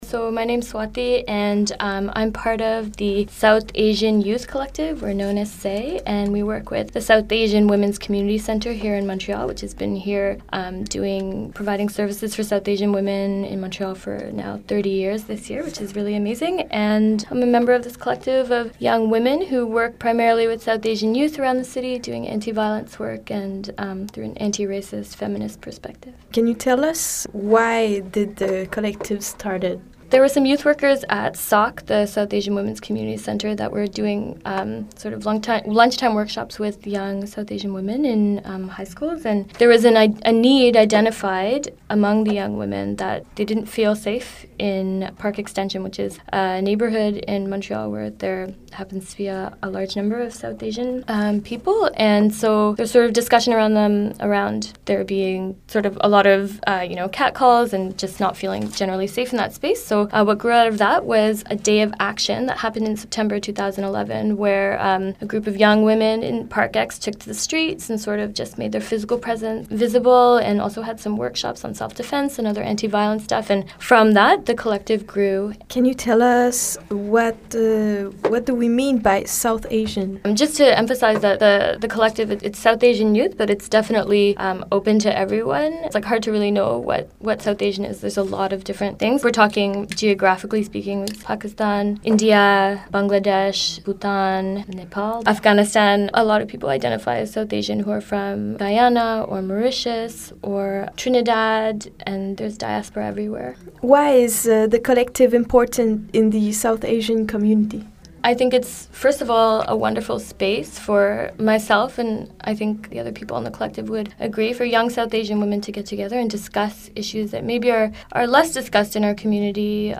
aired during Blue Print Radio on CKUT 90.3 FM
interview_with_say.mp3